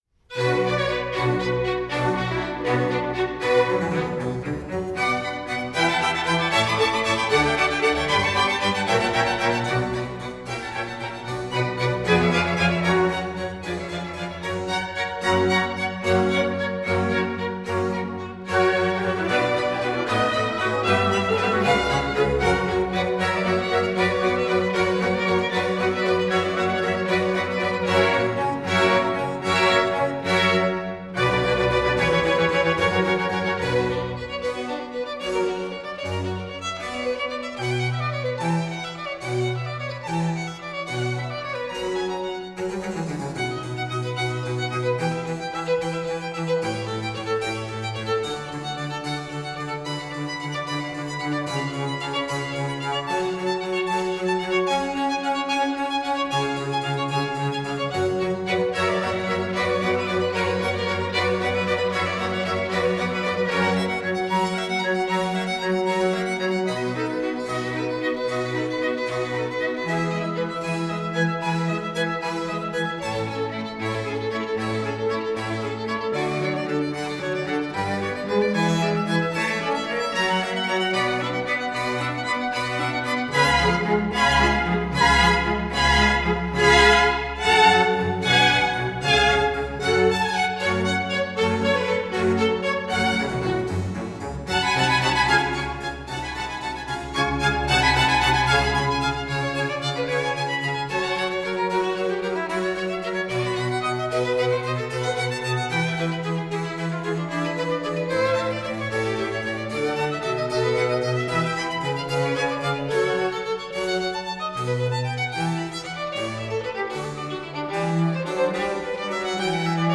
antonio-vivaldi-concerto-grosso-for-four-violins-cello-strin.mp3
antonio_vivaldi_concerto_grosso_for_four_violins_cello_strin.mp3